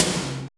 SEATOM HI.wav